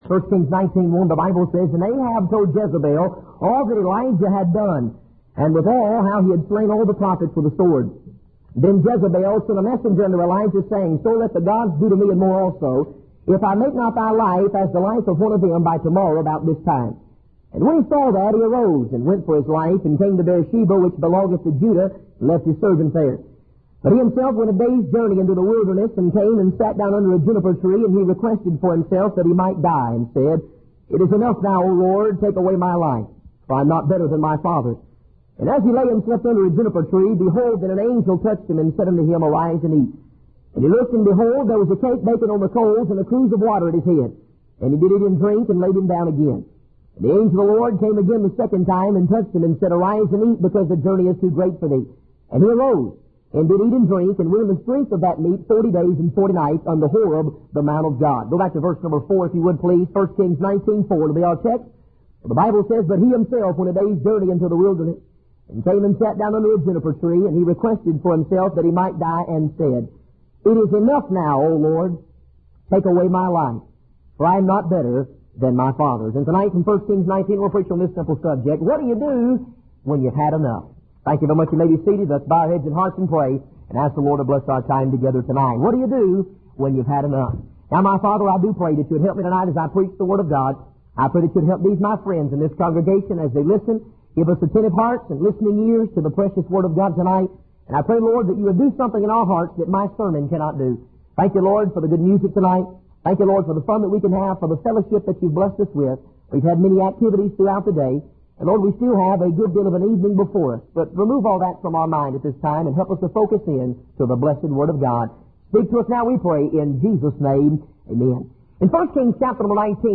In this sermon, the preacher emphasizes the importance of relying on the word of God as our sustenance. He encourages the congregation to prioritize the word of God over worldly distractions such as newspapers, television, and even friends and family. The preacher references the story of Elijah in the Bible, where God provides him with food and water during a time of discouragement and fear.